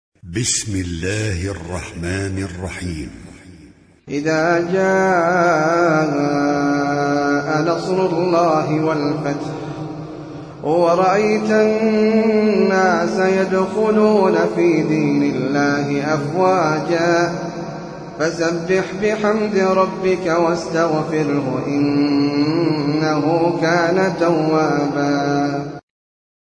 Sûrat An-Nasr (The Help) - Al-Mus'haf Al-Murattal
high quality